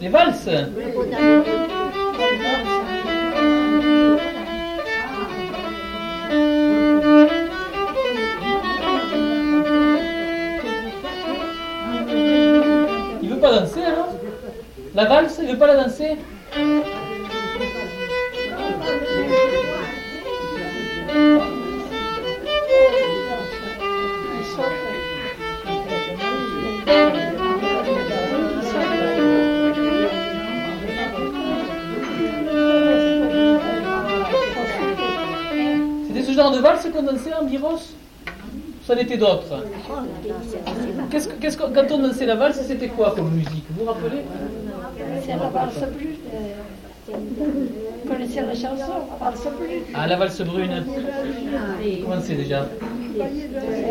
Aire culturelle : Couserans
Lieu : Castillon-en-Couserans
Genre : morceau instrumental
Instrument de musique : violon
Danse : valse